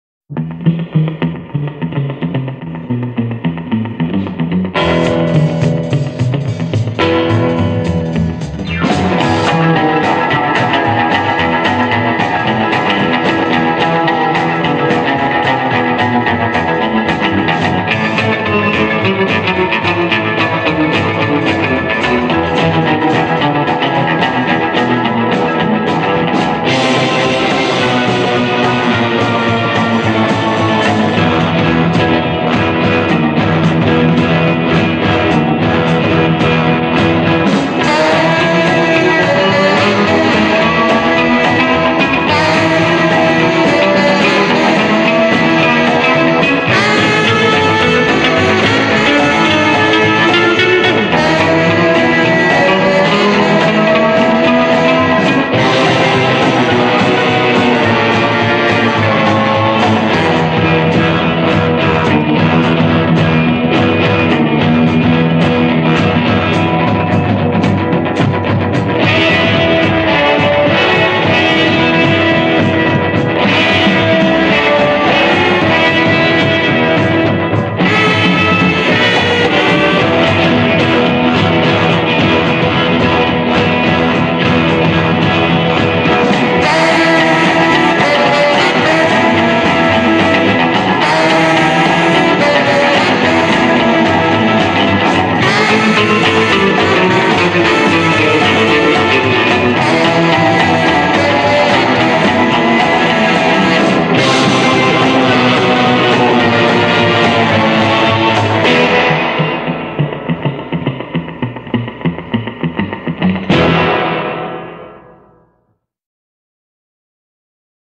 this was an instrumental group
guitars